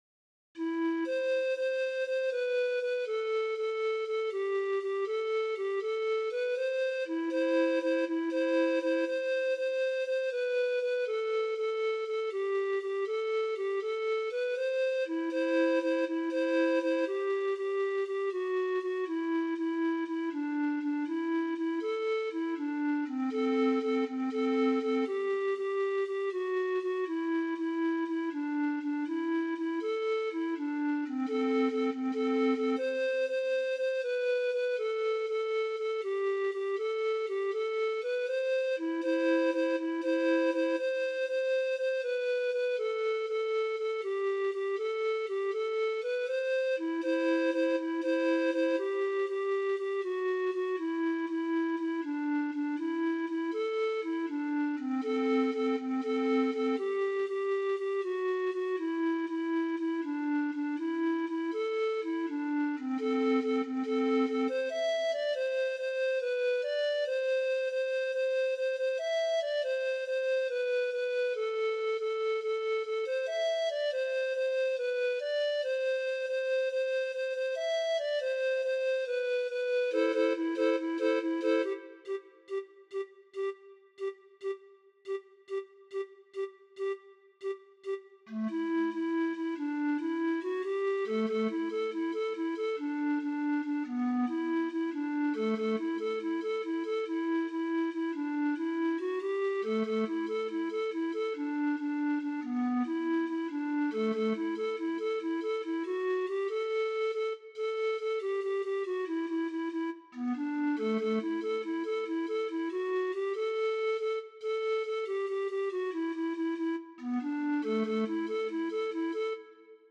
Welcome! The Zampoñistas are Australia’s premier Bolivian Pan Pipe Marching Band.
Illapu_all_Gmaj.mp3